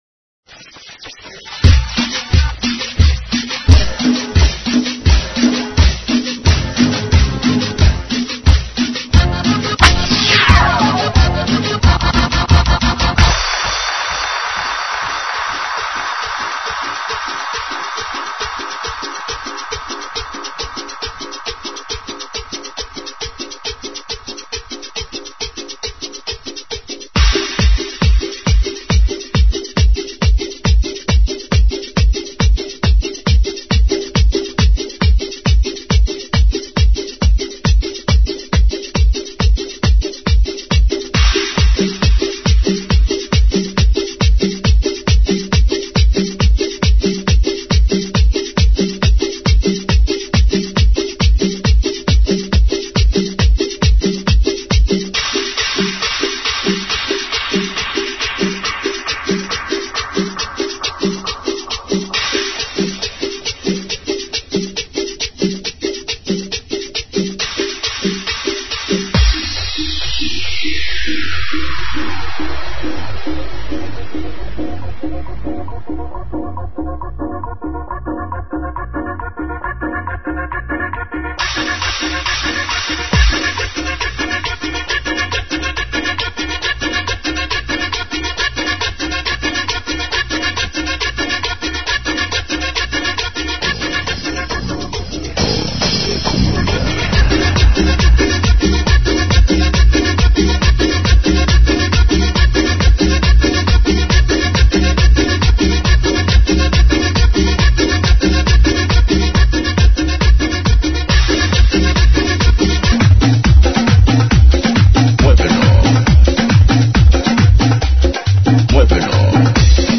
GENERO: LATINO – TRIBAL